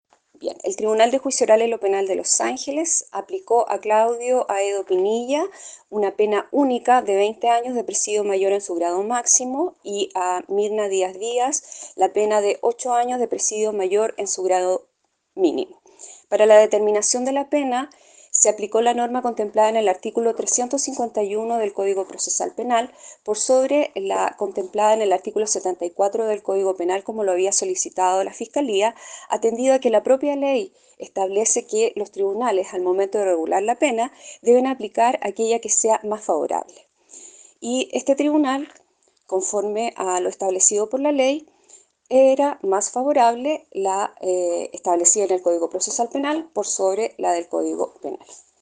La magistrada Marisol Panes explicó por qué se llegó a esa pena.